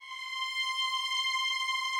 Added more instrument wavs
strings_072.wav